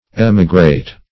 Emigrate \Em"i*grate\, a.